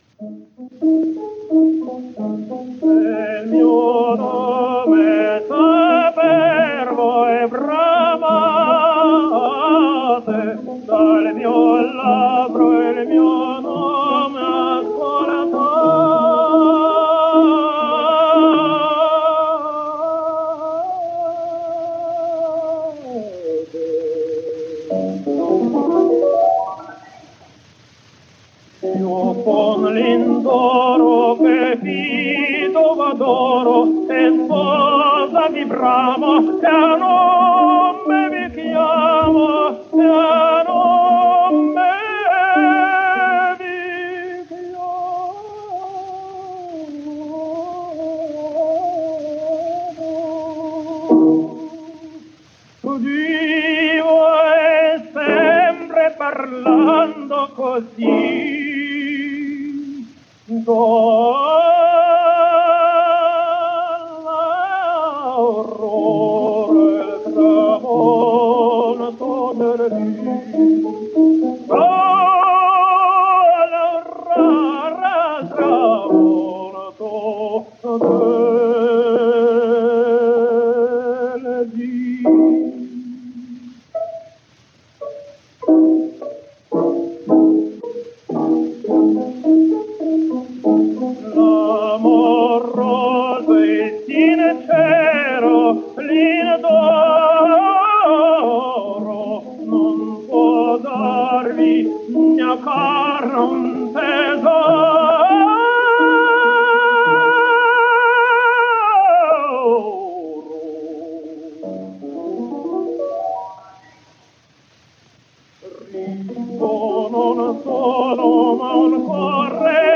Belarusian Tenor